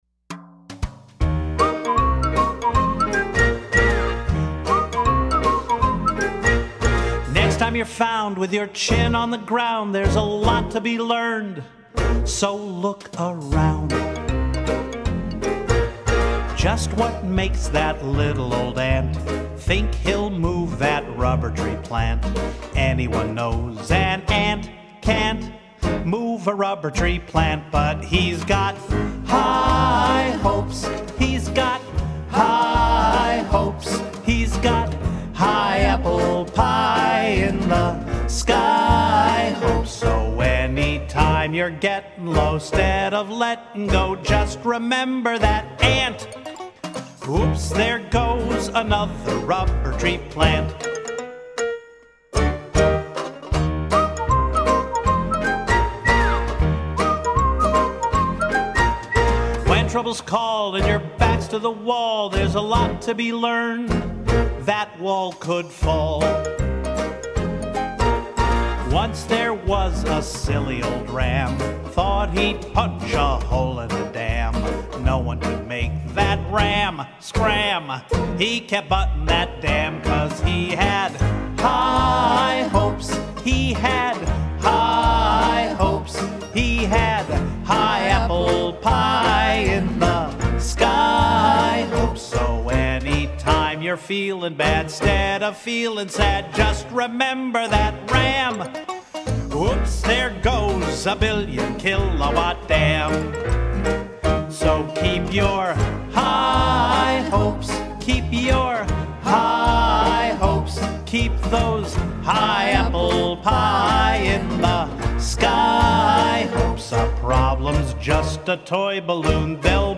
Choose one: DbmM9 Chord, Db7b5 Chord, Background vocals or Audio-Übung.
Background vocals